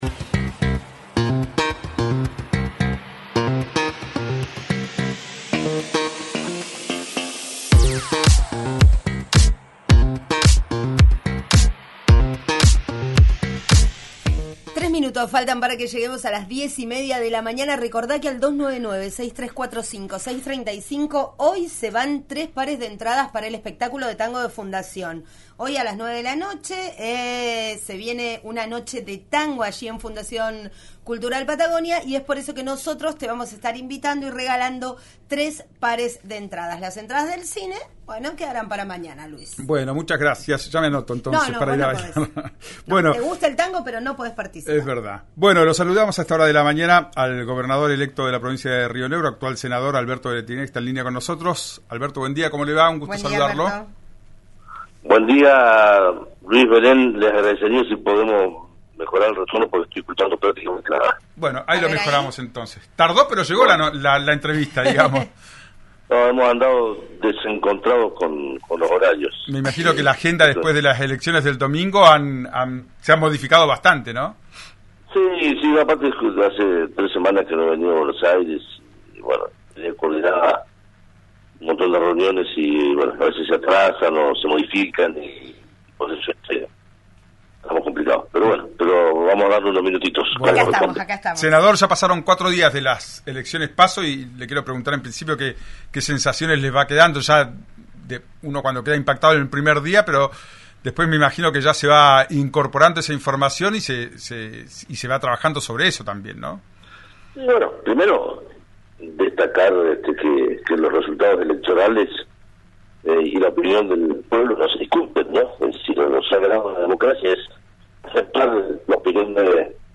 En diálogo con RÍO NEGRO RADIO, el mandatario electo opinó sobre el candidato por La Libertad Avanza, el más votado en todo el país, y las implicancias de su sorpresivo triunfo.